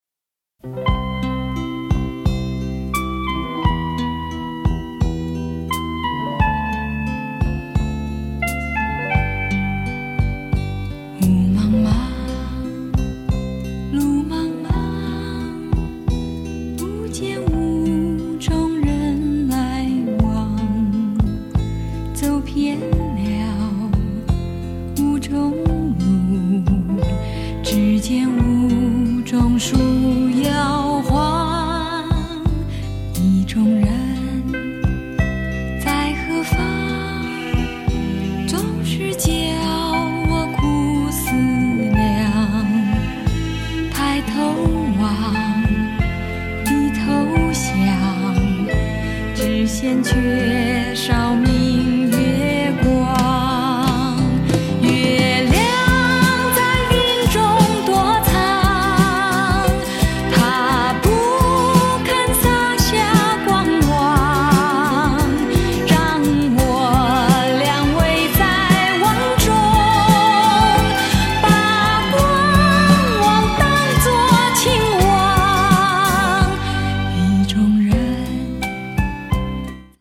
★ 虛無縹緲的天籟嗓音，蘊涵夢幻般的極致柔美！
★ 細膩幽邃的優雅歌聲，瀰漫氤氳般浪漫的韻味！